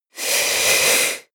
Royalty free sounds: Breathing